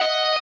guitar_002.ogg